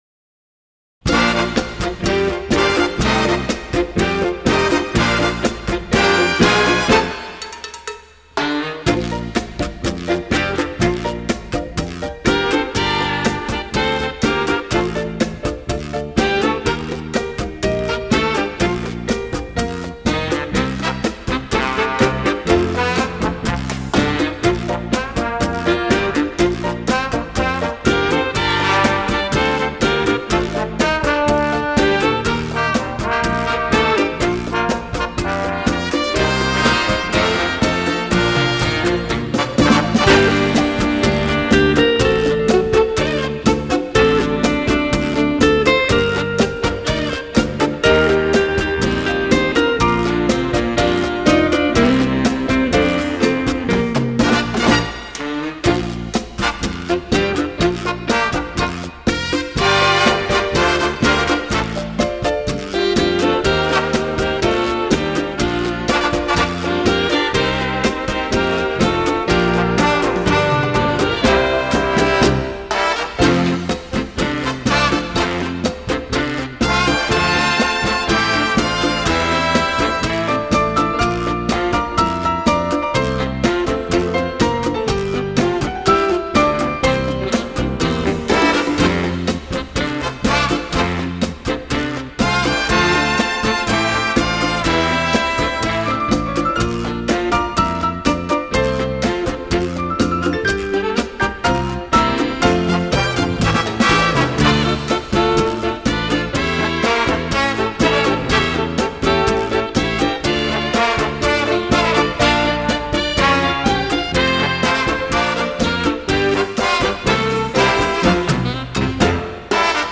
日本歌谣跳舞音乐